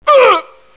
mil_pain1.wav